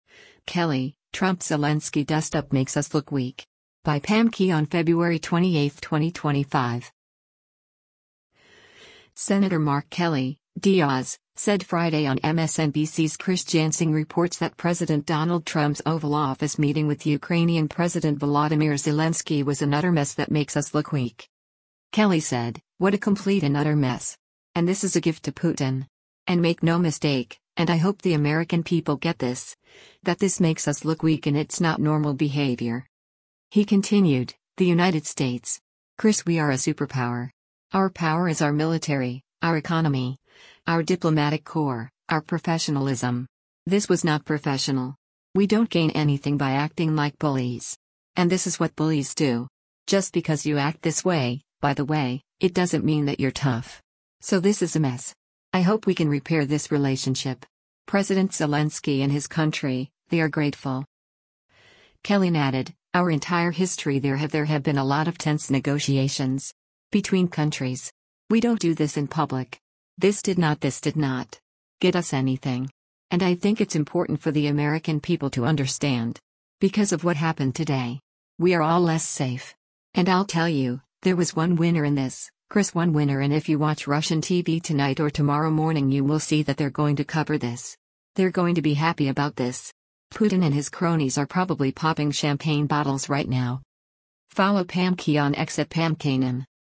Senator Mark Kelly (D-AZ) said Friday on MSNBC’s “Chris Jansing Reports” that President Donald Trump’s Oval Office meeting with Ukrainian President Volodymyr Zelensky was an “utter mess” that “makes us look weak.”